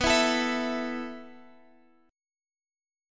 A5/B chord